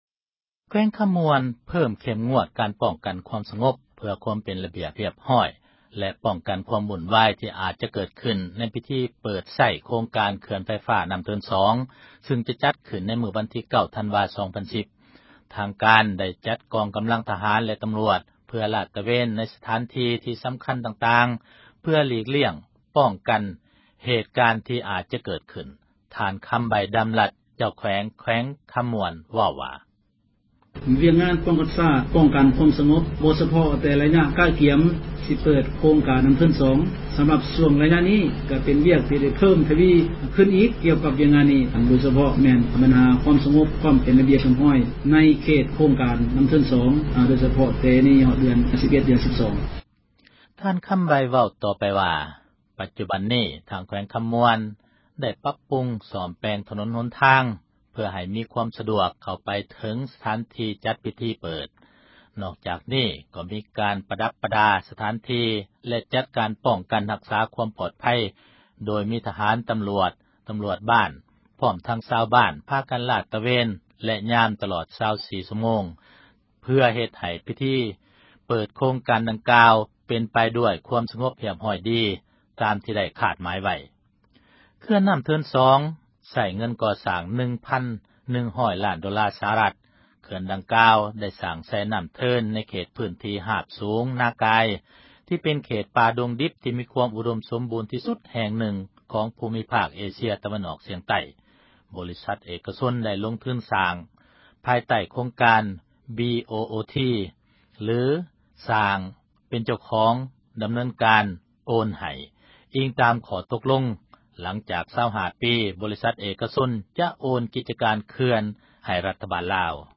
ທາງການໄດ້ ຈັດກອງກຳລັງ ທະຫານ ແລະ ຕຳຣວດ ເພື່ອລາດຕະເວນ ໃນສະຖານທີ່ ທີ່ສຳຄັນ ຕ່າງໆ ເພື່ອຫລີກລ້ຽງ ປ້ອງກັນ ເຫດການທີ່ ອາດຈະເກີດຂຶ້ນ. ທ່ານຄຳໄບ ດຳລັດ ເຈົ້າແຂວງ ຄຳມ່ວນ ເວົ້າວ່າ: